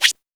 PERC.14.NEPT.wav